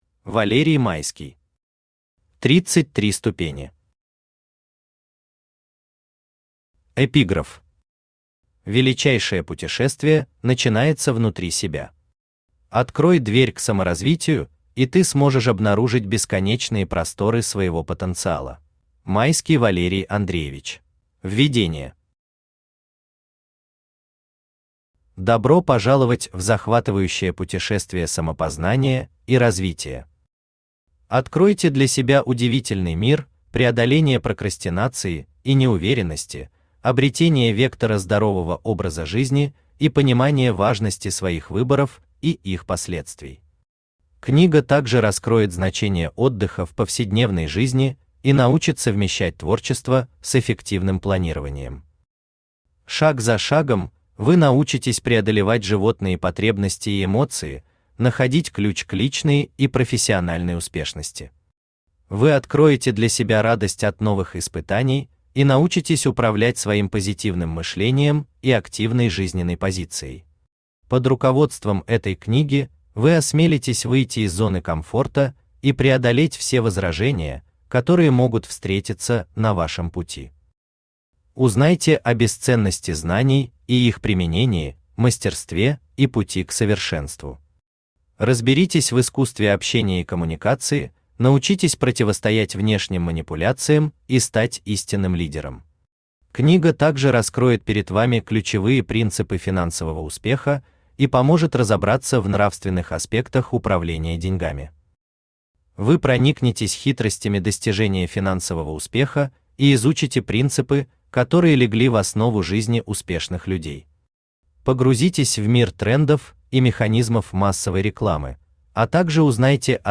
Aудиокнига 33 ступени Автор Валерий Майский Читает аудиокнигу Авточтец ЛитРес.